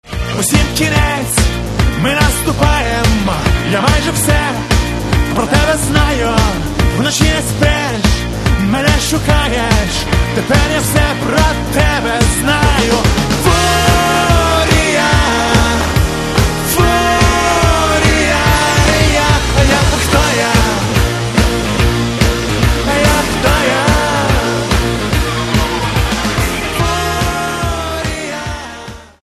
Каталог -> Рок и альтернатива -> Поп рок